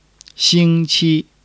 xing1--qi1.mp3